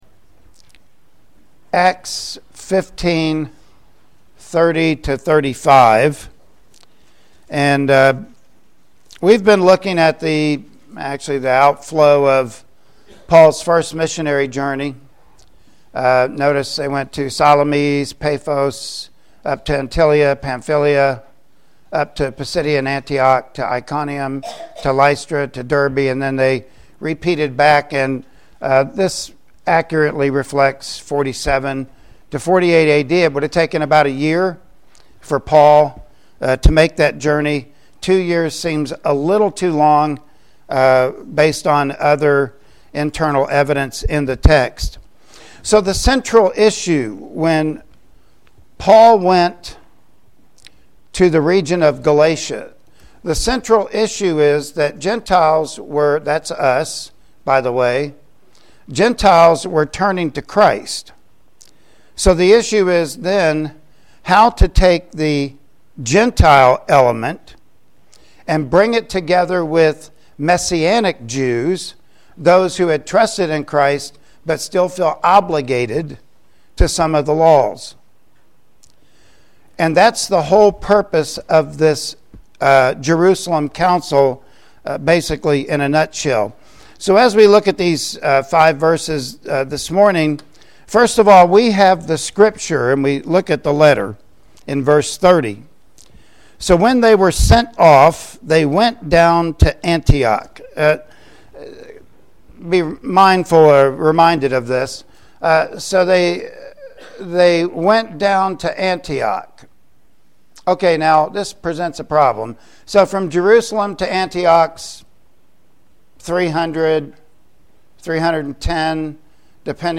Passage: Acts 15:30-35 Service Type: Sunday Morning Worship Service Topics